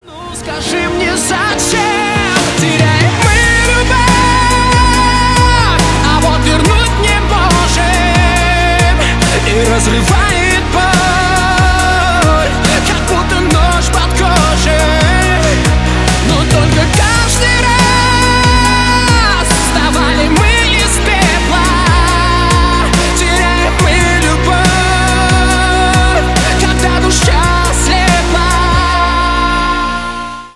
громкие
поп